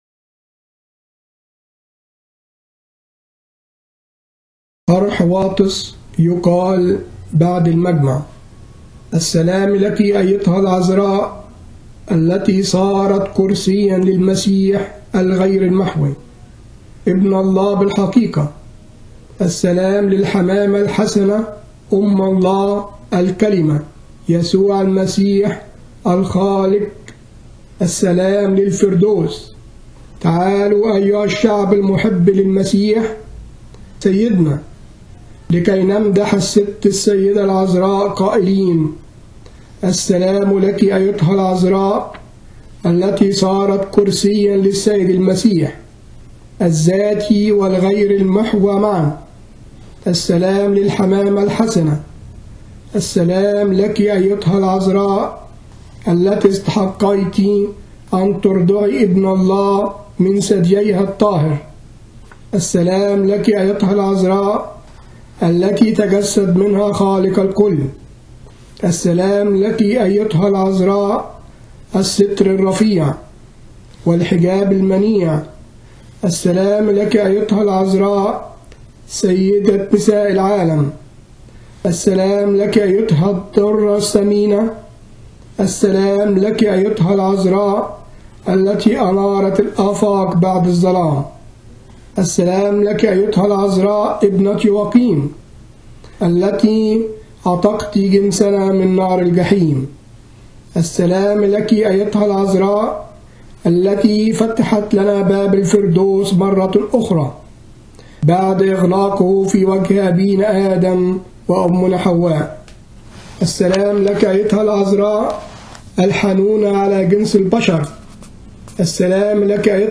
يقال في تسبحة نصف الليل بشهر كيهك